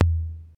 TOM XC.TOM0J.wav